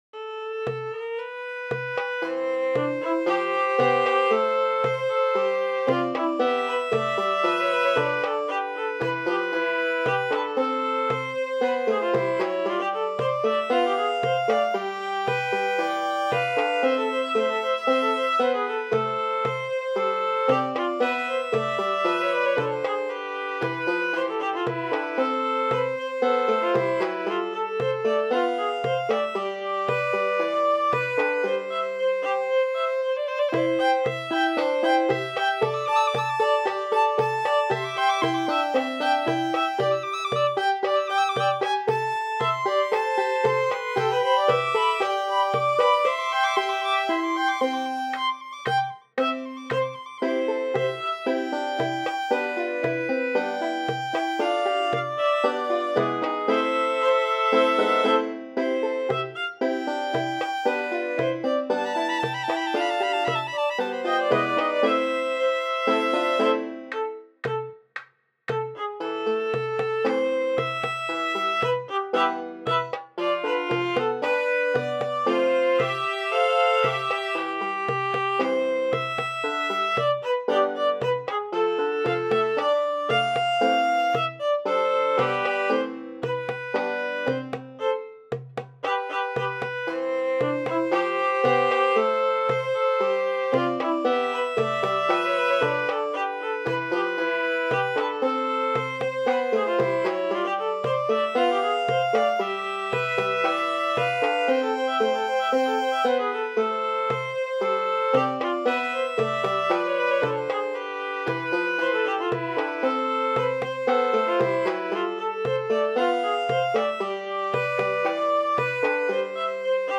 two main violins, percussion & a banjo